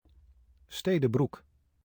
Stede Broec (Dutch: [ˌsteːdə ˈbruk]